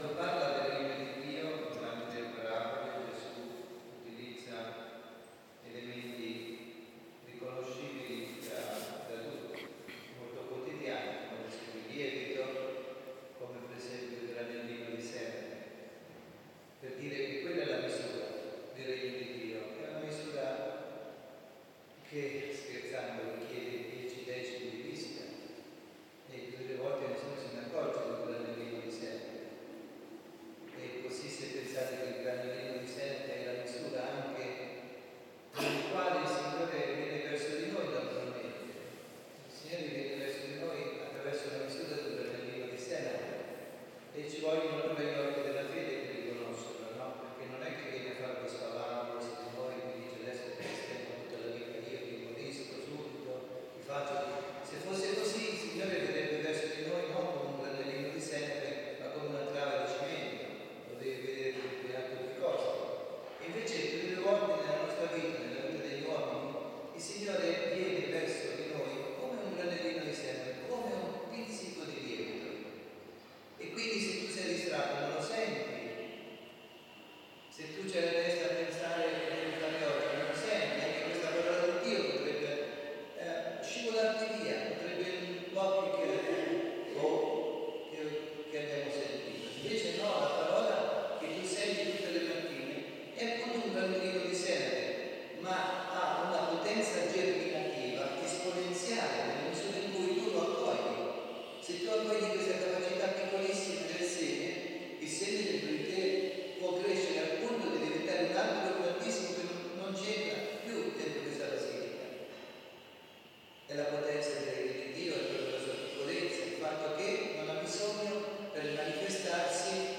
Omelia
dalla Basilica di San Nicola – Tolentino